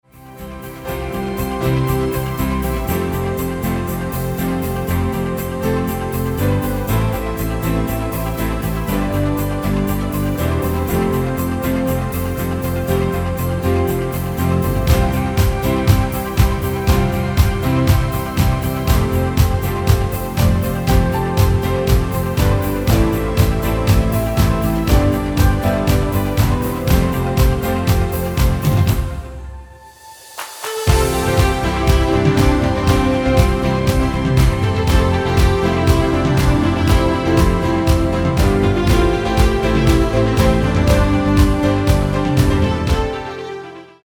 Demo/Koop midifile
Genre: Actuele hitlijsten
Toonsoort: C
Demo = Demo midifile